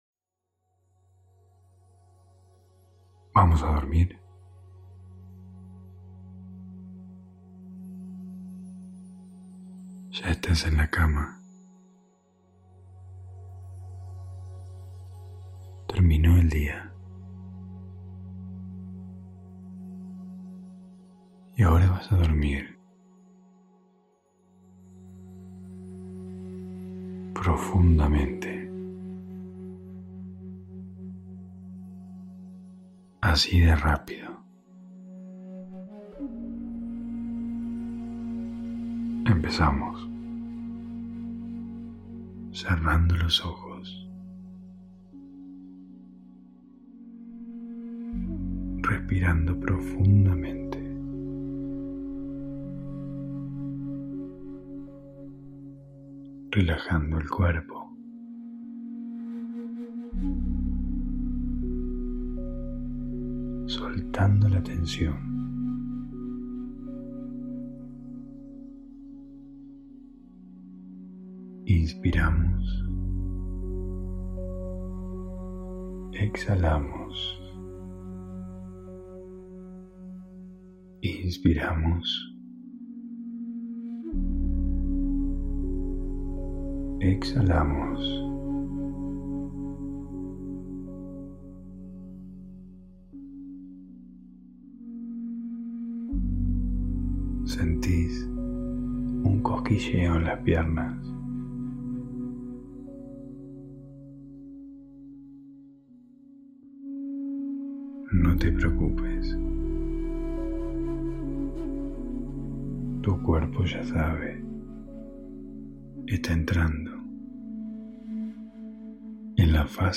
Hipnosis para dormir ✨
[Altamente recomendable escucharlo con auriculares ] Hosted on Acast.